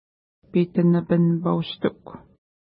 Pronunciation: pi:tənəpən-pa:wstuk